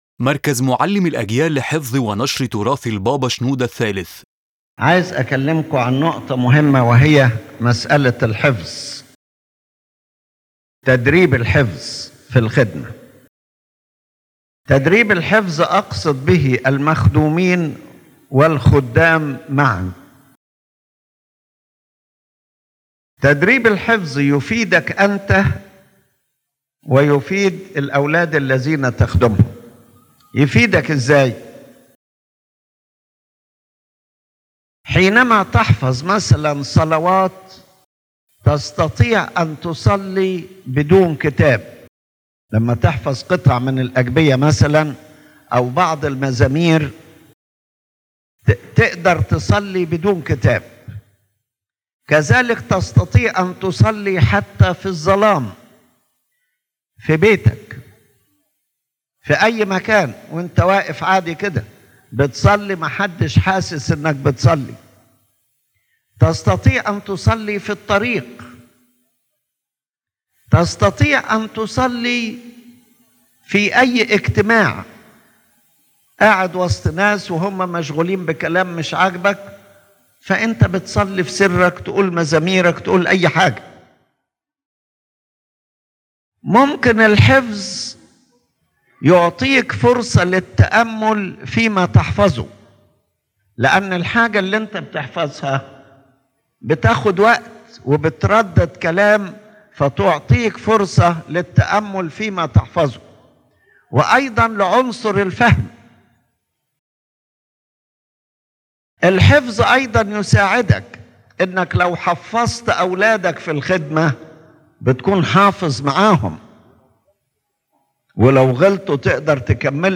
His Holiness Pope Shenouda III delivers a lecture on the importance of training in memorization in Christian ministry, explaining how memorization nourishes prayer, contemplation, spiritual teaching, and protects from distraction of mind and falling into sins.